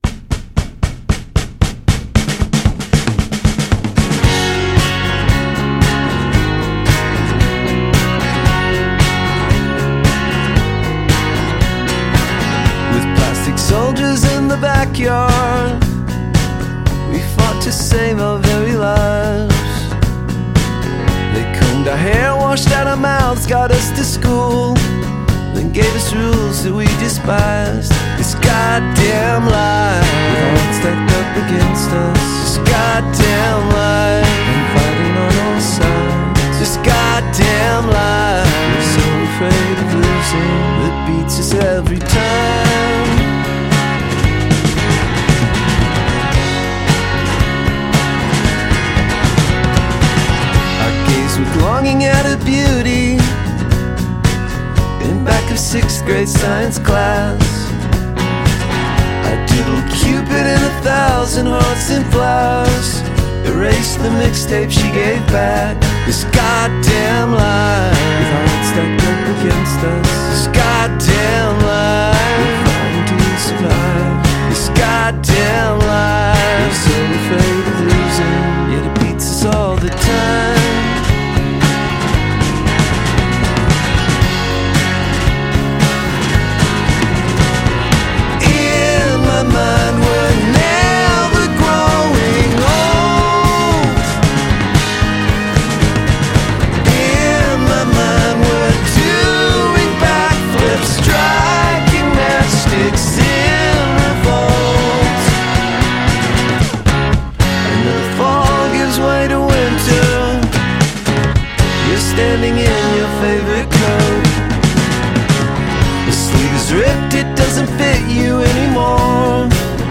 which made a lot of power pop year-end top ten lists.